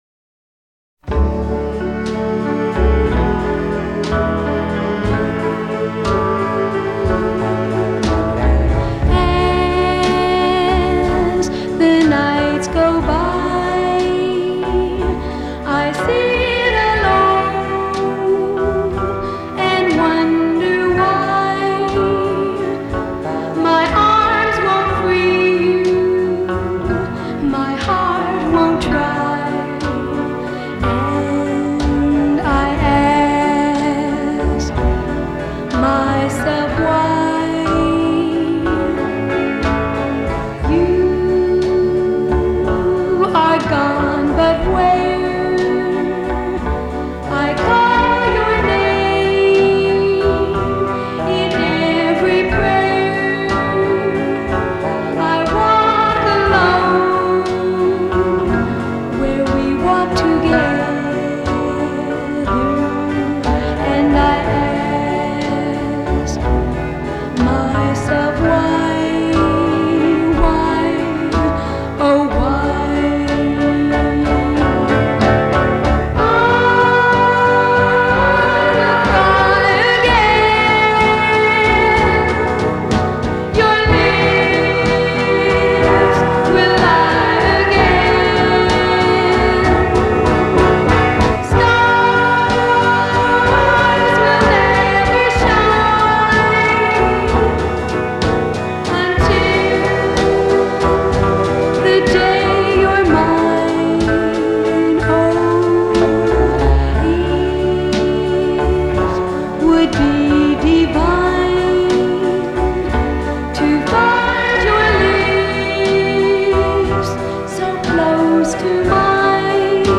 Жанр: Pop, Oldies, Doo-Woop
back vocals, guitar, piano
lead vocals
drums